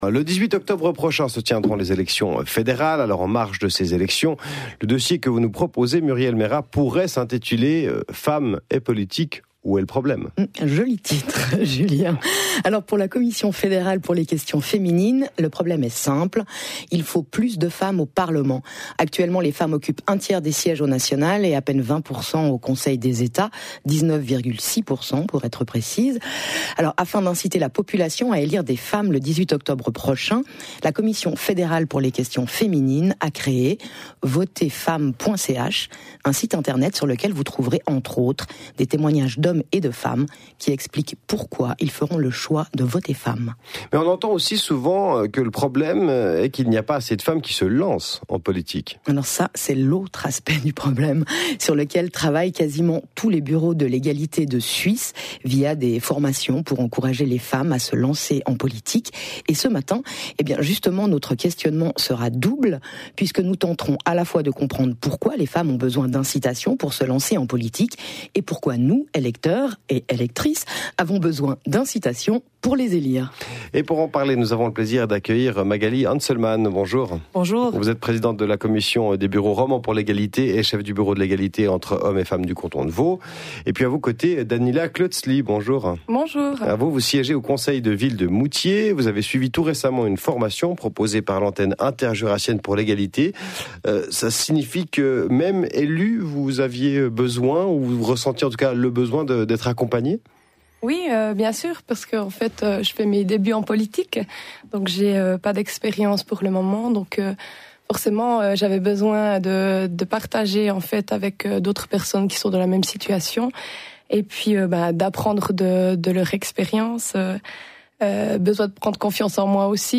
Interview audio, La première